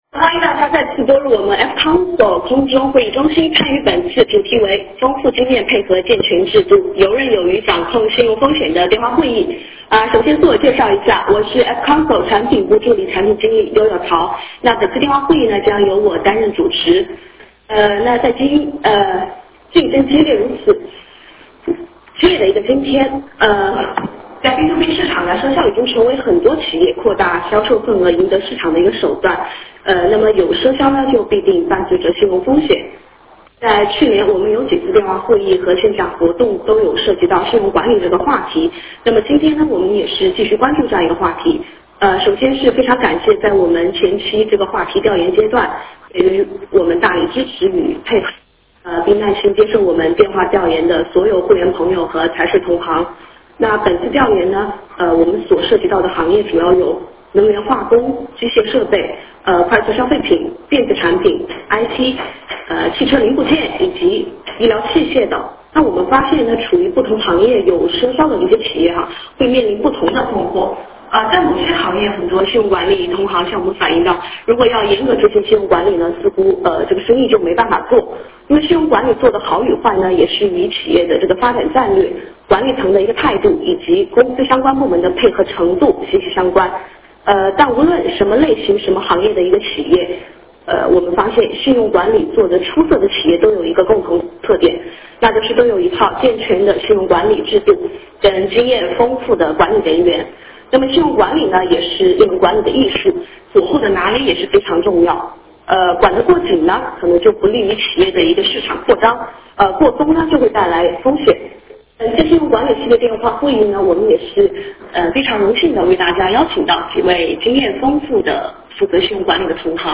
形式： 电话会议
Q&A互动环节